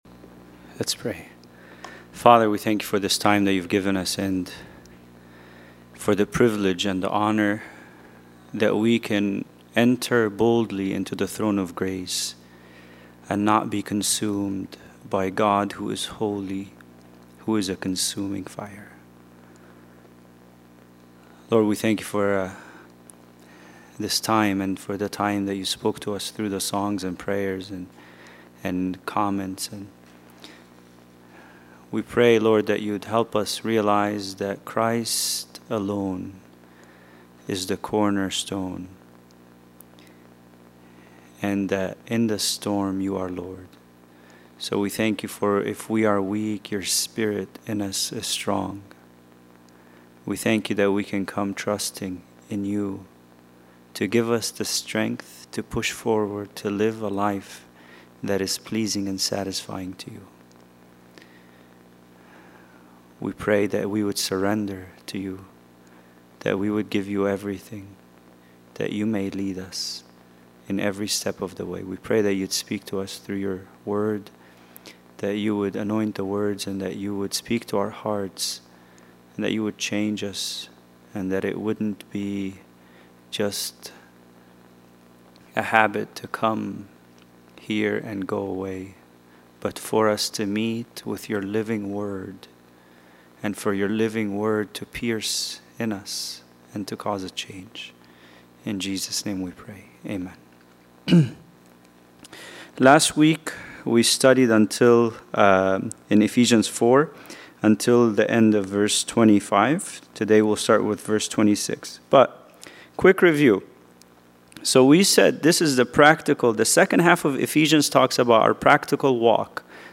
Bible Study: Ephesians 4:26-27